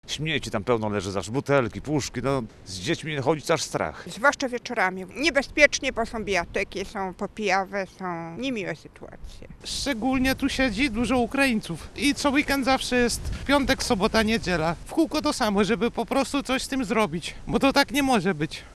Co mówią mieszkańcy miasta: